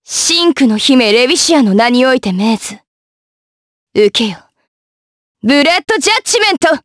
Lewsia_B-Vox_Skill7_jp.wav